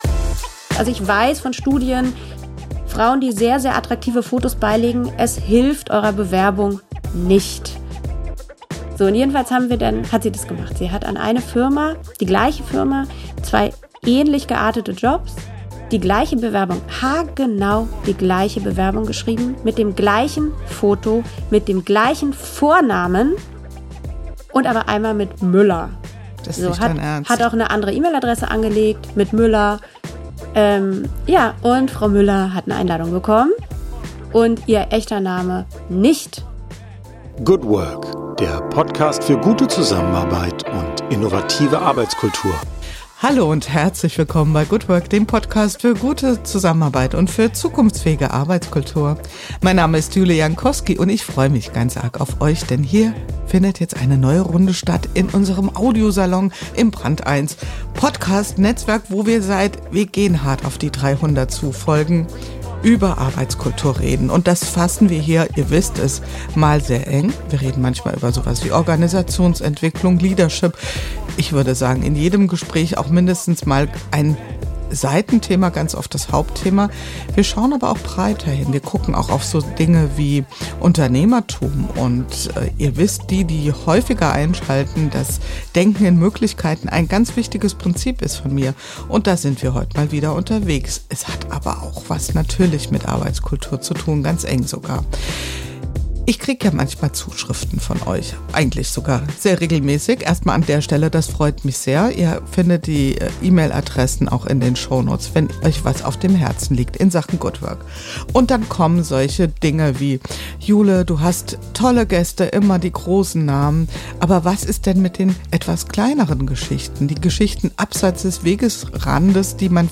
Ein Gespräch über Bias, Verantwortung und die Zukunft der Arbeit: klar, fundiert und unbequem.